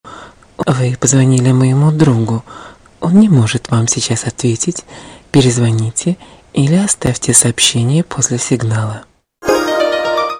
Главная » 2012 » Март » 25 » Нарезку женский голос.
Категория: На автоответчик